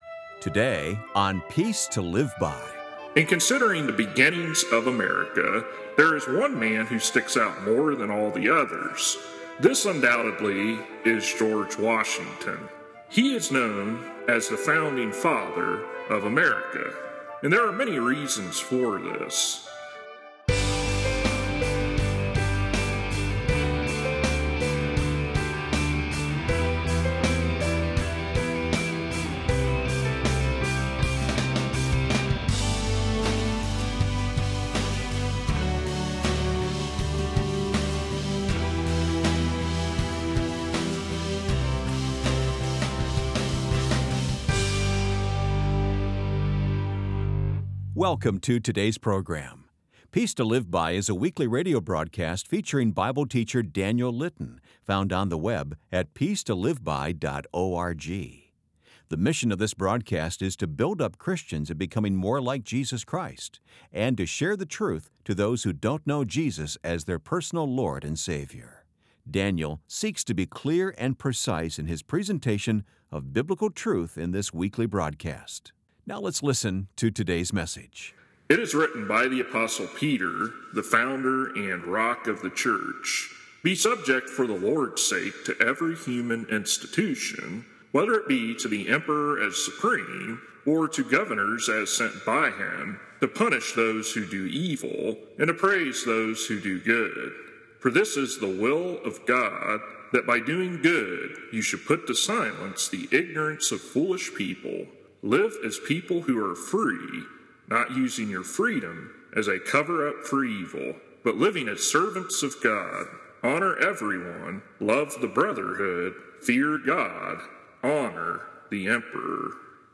Live as people who are free, not using your freedom as a cover-up for evil, but living as servants Continue reading sermon...